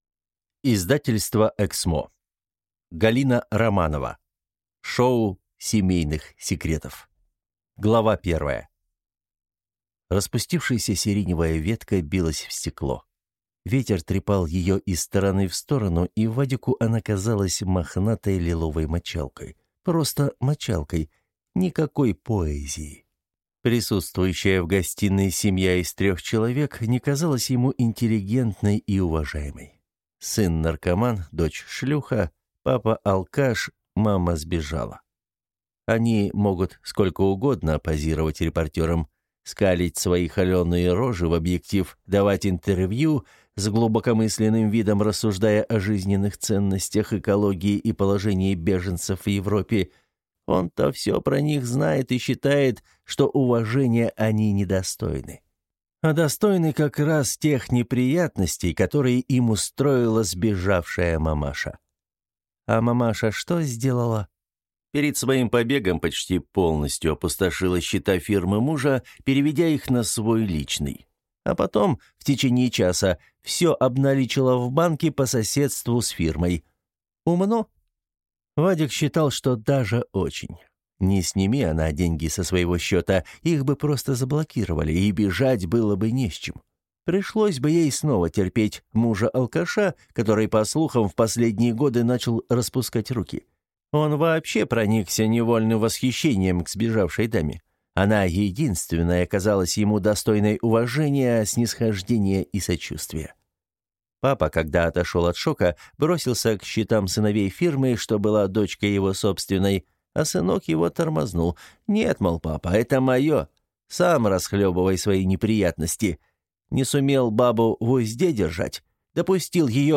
Аудиокнига Шоу семейных секретов | Библиотека аудиокниг